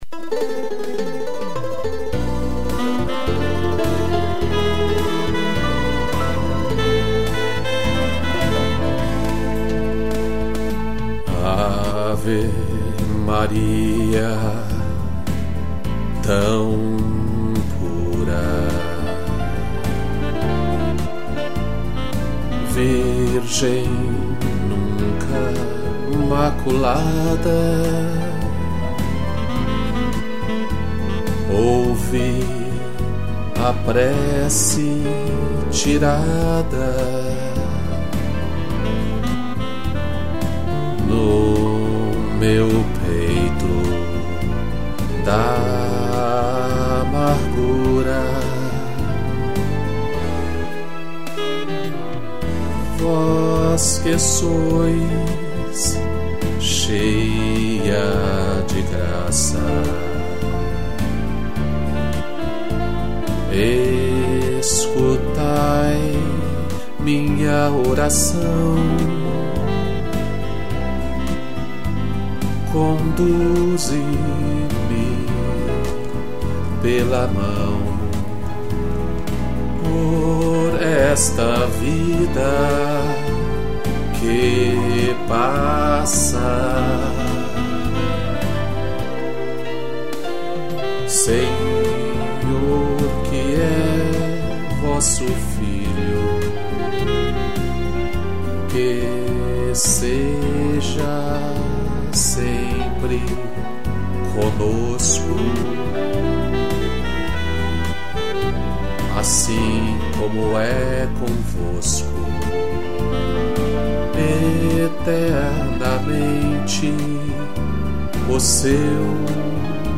piano, cello, violino e sax